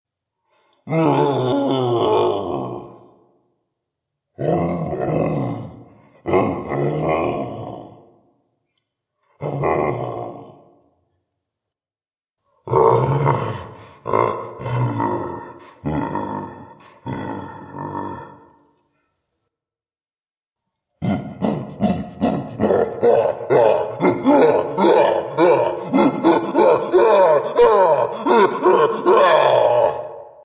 Cavegorillawav 14739 (audio/mpeg)